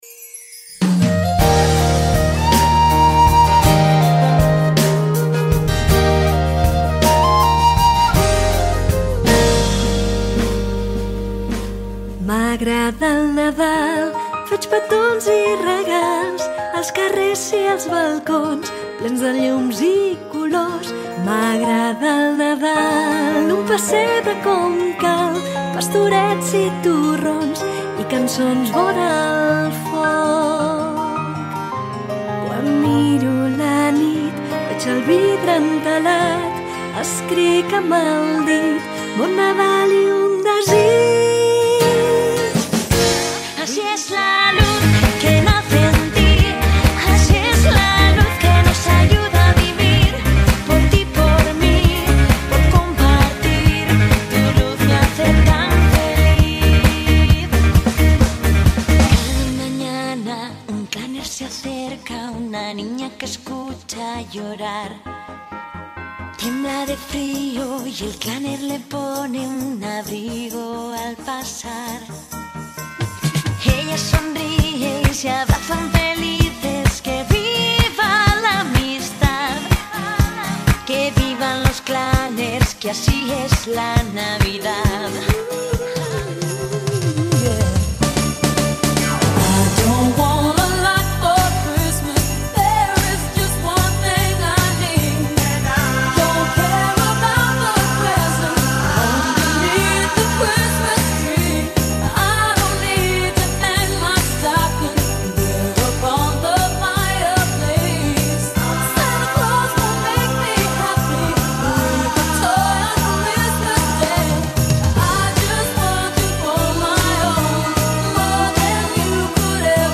El dimarts 20 de desembre hem tornat a celebrar el tradicional concert de Nadal al Gerbert.